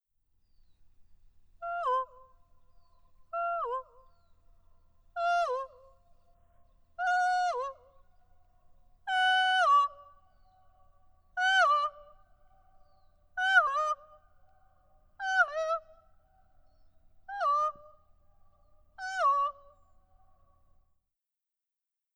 Tällainen kaukainen kaiku vastaa huhuiluihin vaimeasti ja pitkällä aikaviiveellä.
Huhuilua Luumäen Kalamaniemen (I) kalliomaalauksen edustalla, 43 metrin päässä kalliosta.